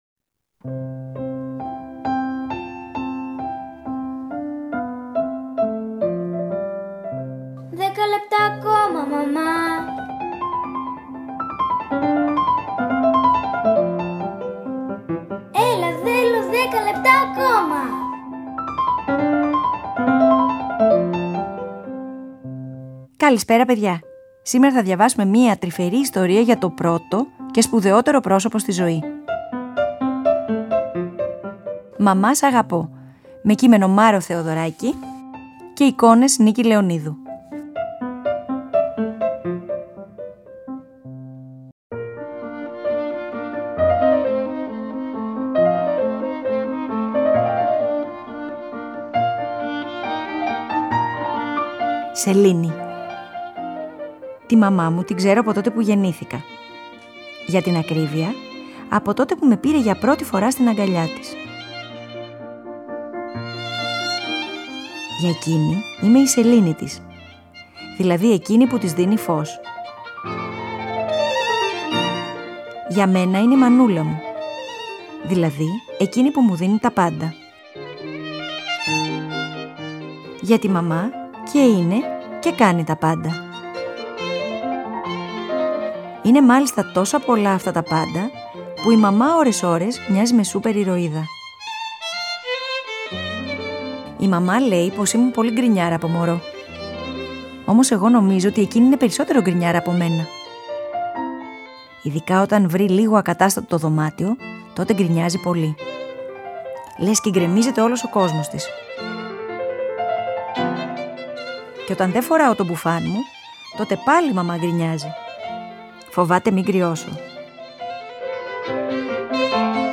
Διαβάζουμε μια τρυφερή ιστορία για το πρώτο και σπουδαιότερο πρόσωπο στη ζωή.